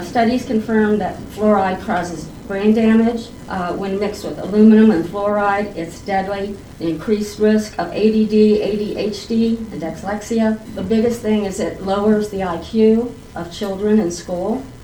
Fluoride Opponents Speak at Council Meeting